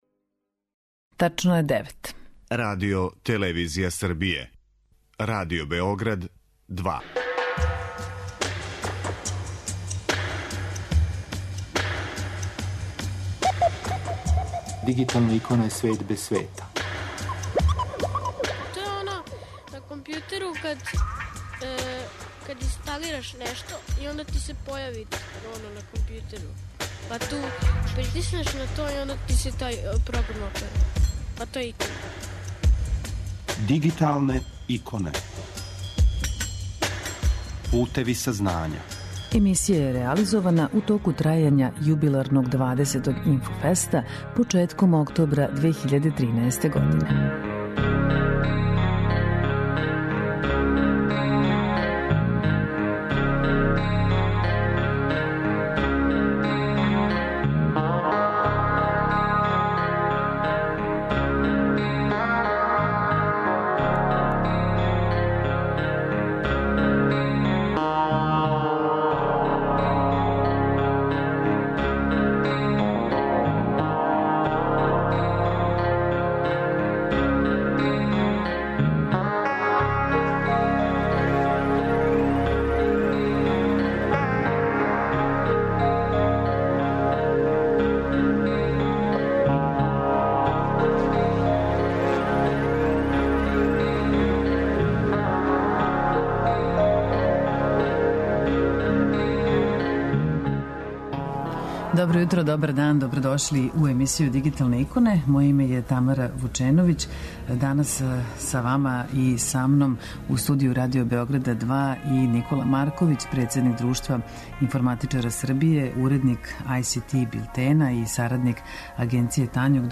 Ова емисија је реализована у току трајања 20. Инфофеста, jедног од највећих информатичких скупова у региону, који је одржан од 29. септембра до 5. октобра прошле године у Будви.